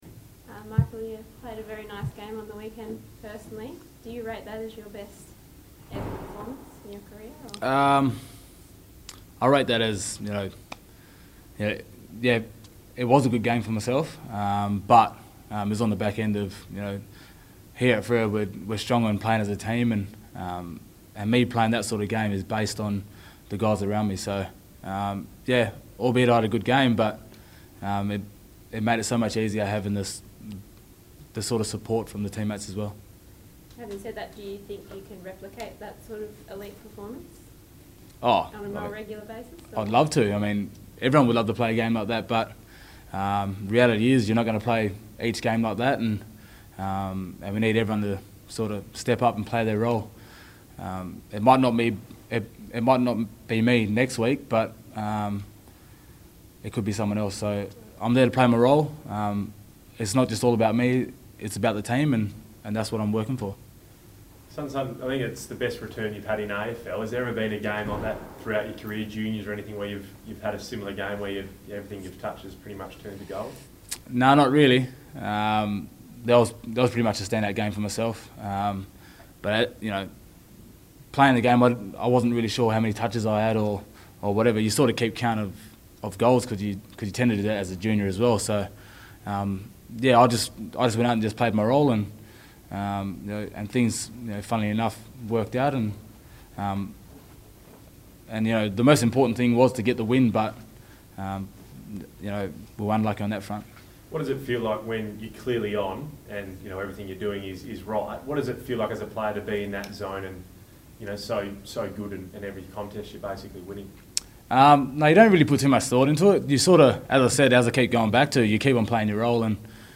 Michael Walters chats to the media after Freo's clash against the Saints.